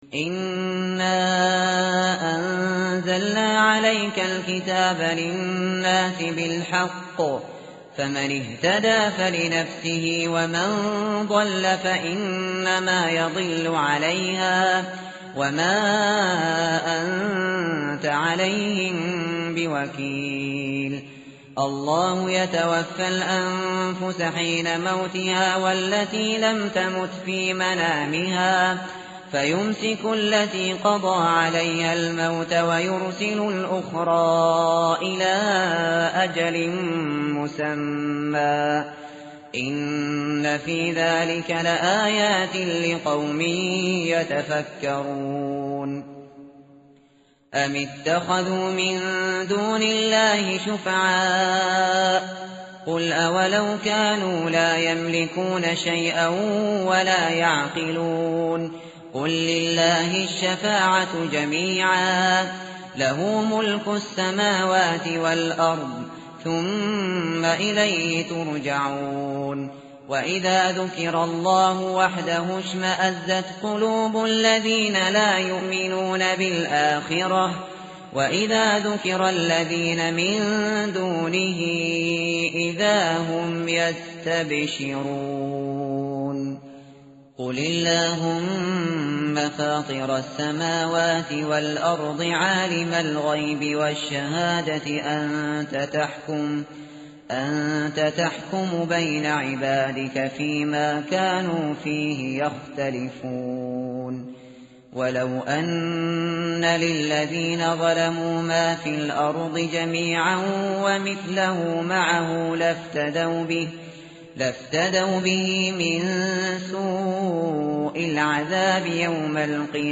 tartil_shateri_page_463.mp3